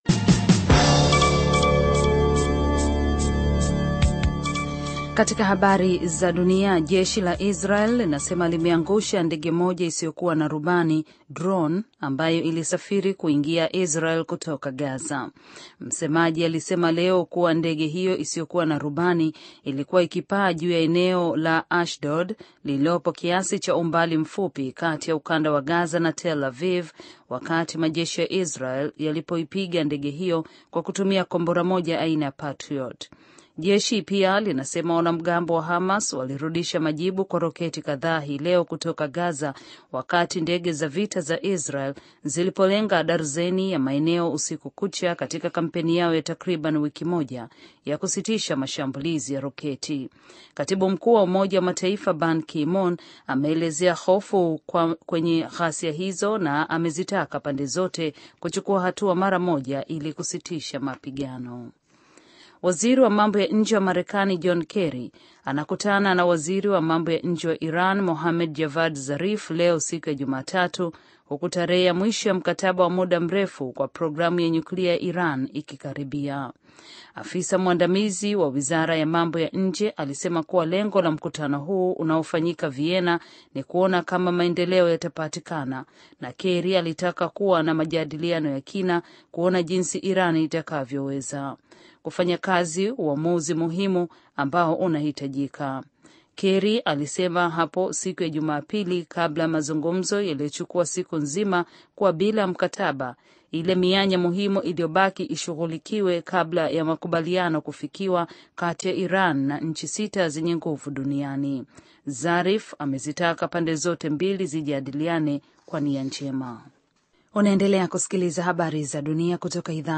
Taarifa ya Habari VOA Swahili -